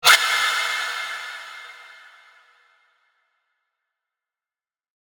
menu-multiplayer-click.ogg